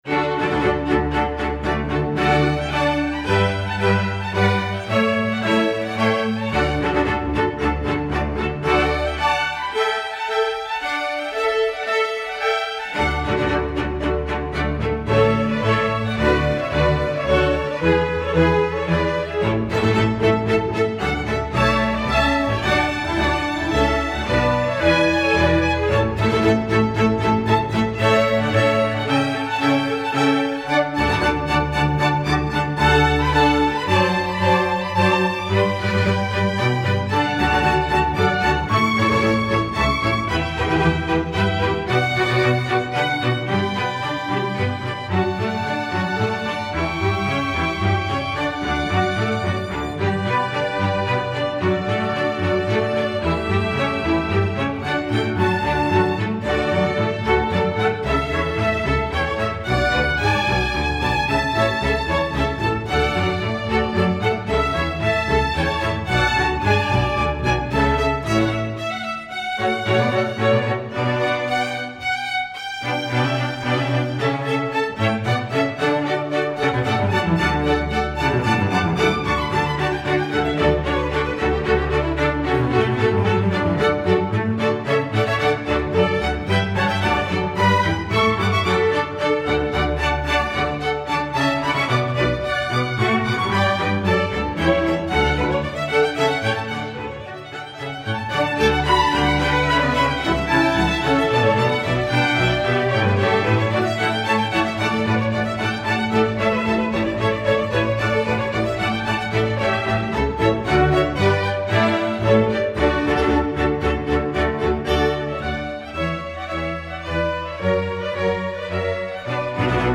Instrumentation: string orchestra
masterwork arrangement